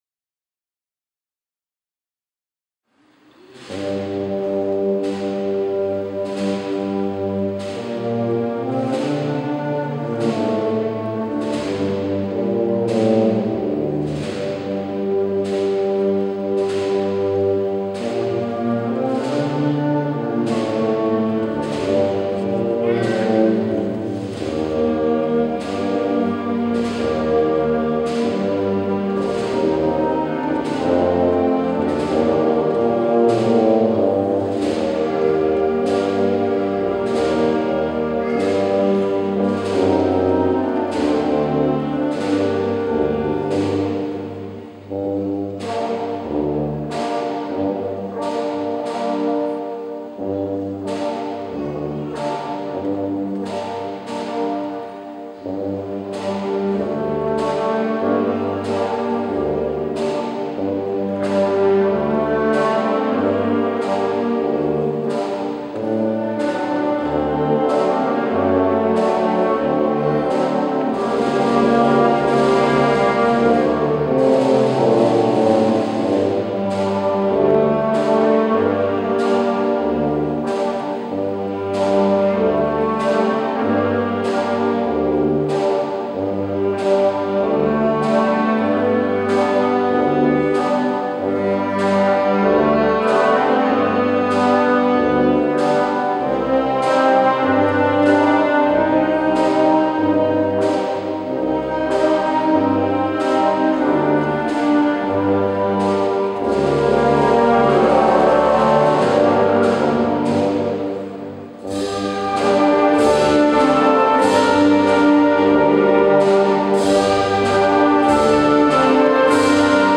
Marcha Procesional para Banda de Música,